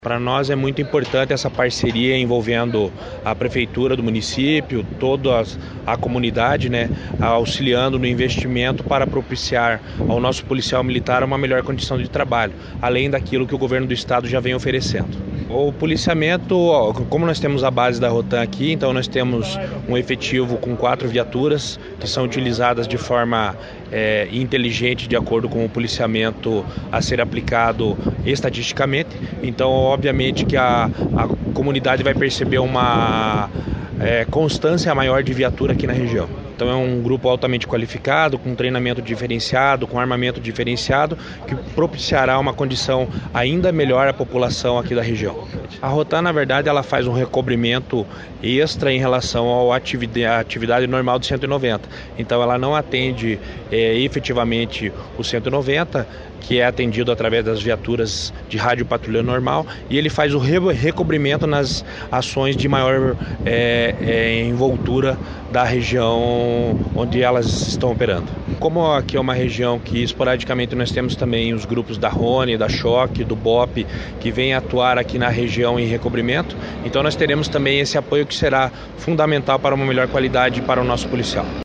Sonora do comandante-geral da PMPR, Coronel Jefferson Silva, sobre a nova base da Rotam em Clevelândia